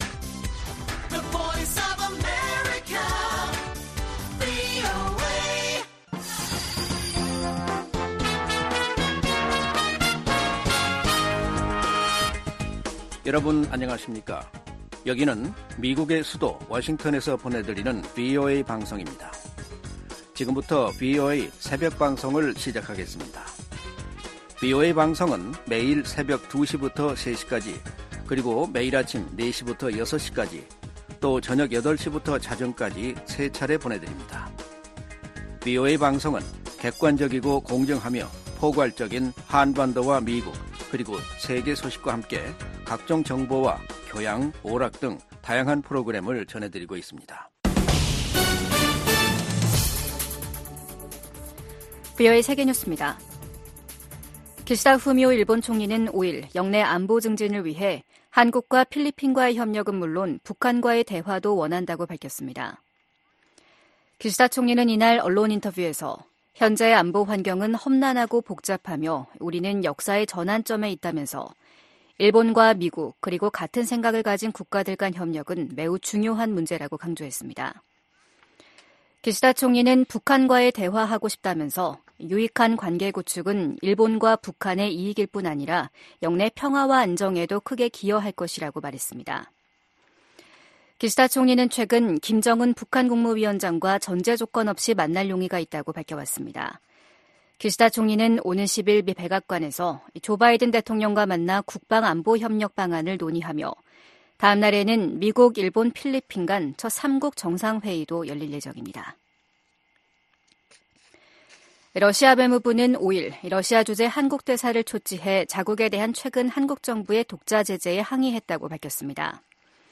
VOA 한국어 '출발 뉴스 쇼', 2024년 4월 6일 방송입니다. 유엔 안보리 대북 결의 이행을 감시하는 전문가패널의 임기 연장을 위한 결의안 채택이 러시아의 거부권 행사로 무산됐습니다. 이에 미국과 한국·일본 등은 러시아를 강하게 비판했습니다.